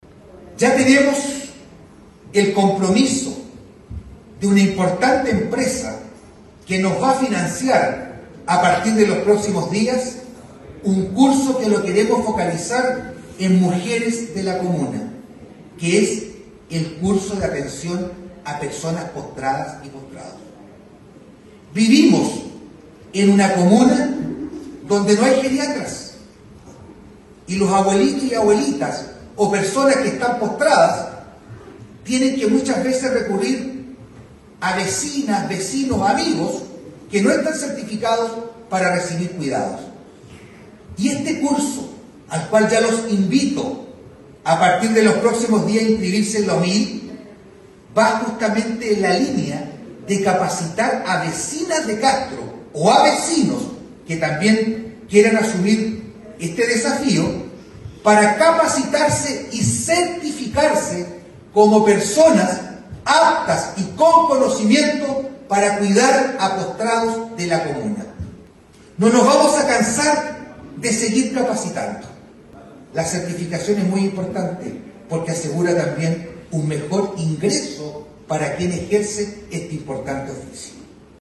ALCALDE-VERA-MAS-CAPACITACION.mp3